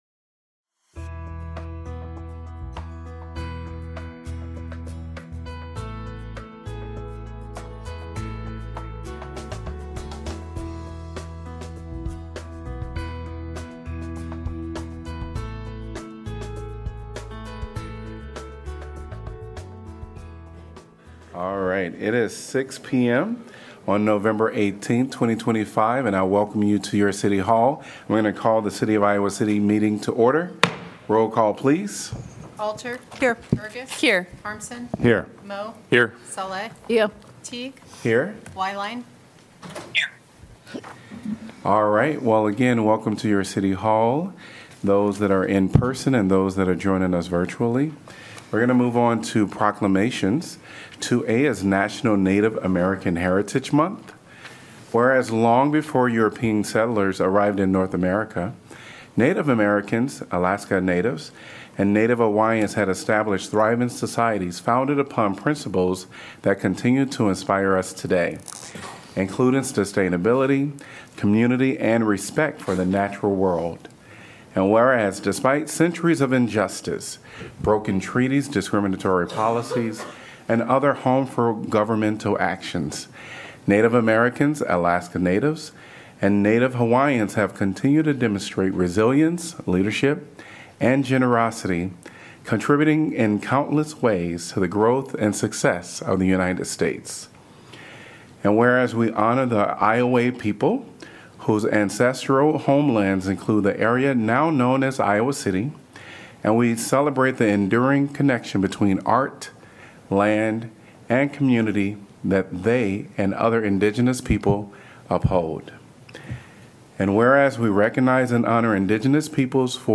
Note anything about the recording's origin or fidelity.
Gavel-to-gavel coverage of the Iowa City City Council meeting, generally scheduled for the first and third Tuesday of each month.